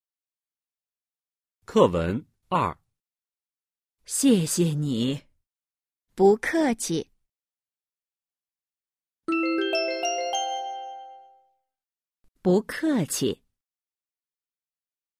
(2) Hội thoại 2